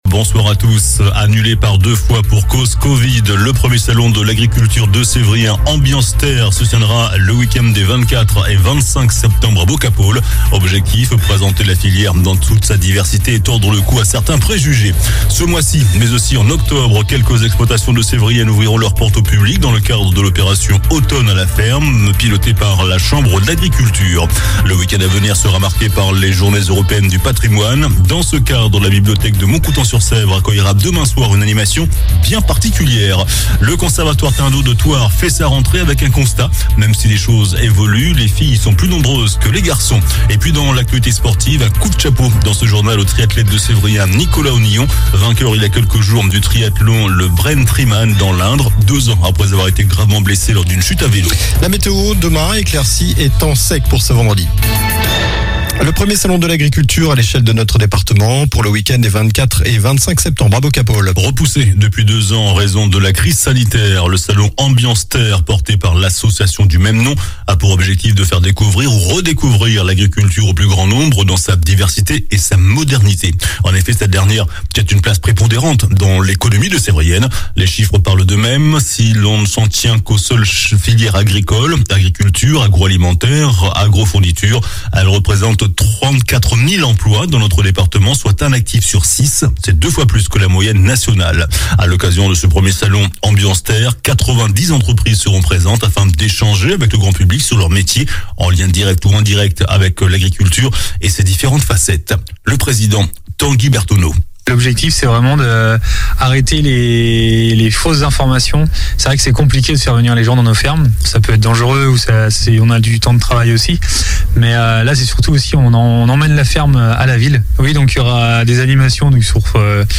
JOURNAL DU JEUDI 15 SEPTEMBRE ( SOIR )